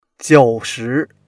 jiu3shi2.mp3